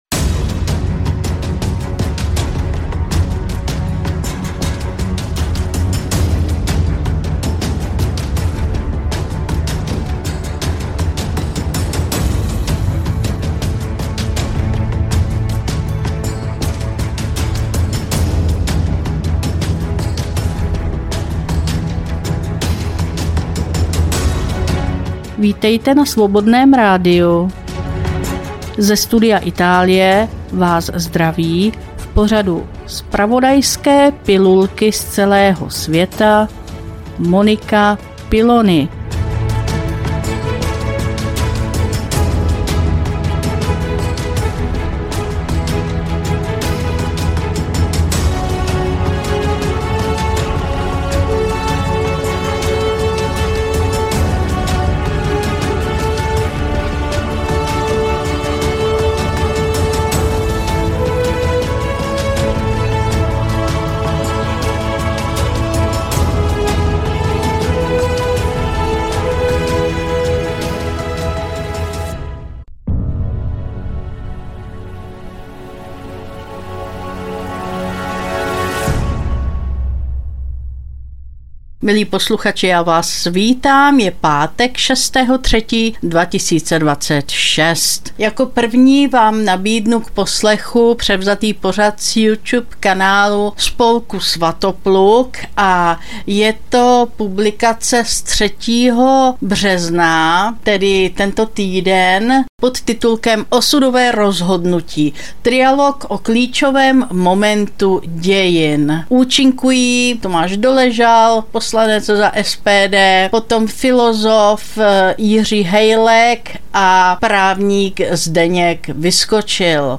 Studio Itálie – Trialog Spolku Svatopluk o klíčovém momentu dějin